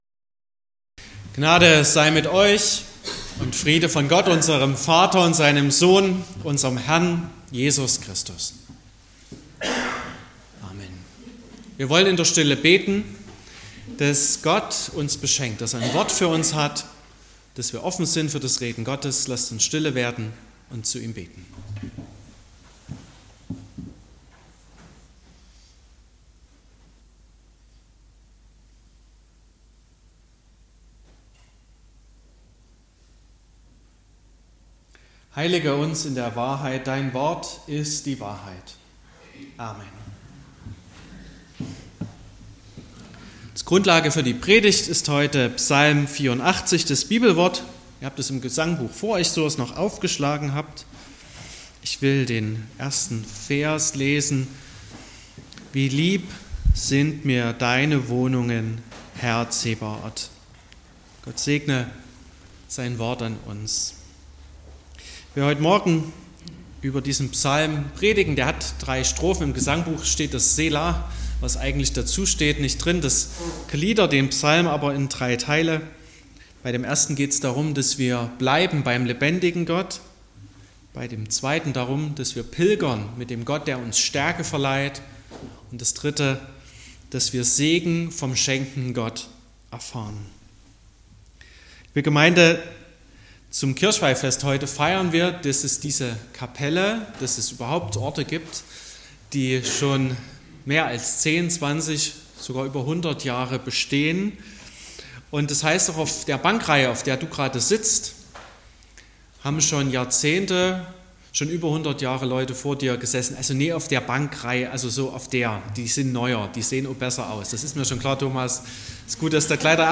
02.11.2025 – Kirchweihfestgottesdienst
Predigt (Audio): 2025-11-02_Der_lebendige_Gott.mp3 (15,7 MB)